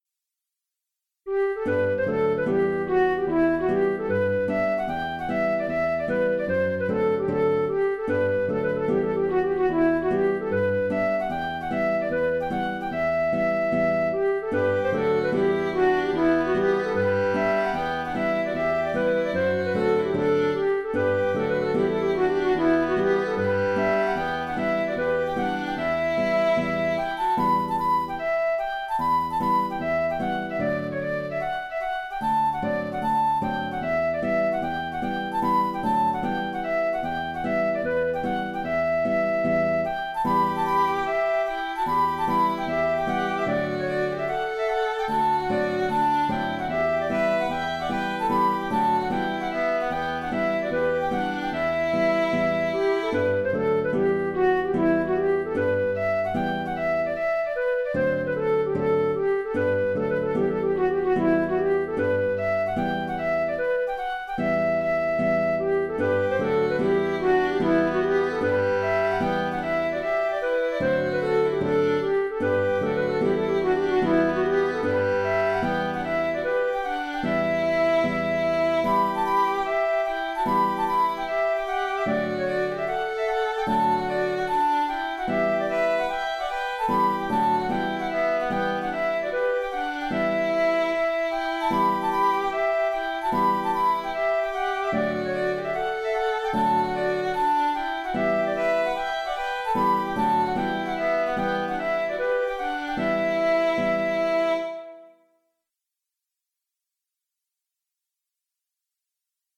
Rights of Man (Hornpipe/Scottish) - Musique irlandaise et écossaise
C’est un « hornpipe », un traditionnel irlandais dont l’origine du titre m’est inconnue.
Un seul contrechant pour ce thème bien connu des amateurs de musique irlandaise.. Auteur : Trad. Irlande.